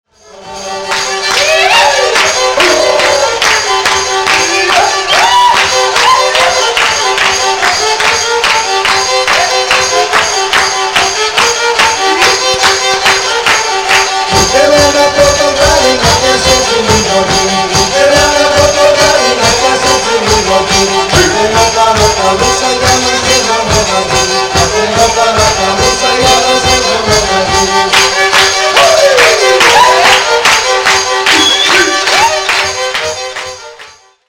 Pontiakos
Pontiaki lyra, vocal
defi, vocal
Group of dancers dancing during recording. 1973